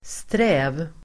Uttal: [strä:v]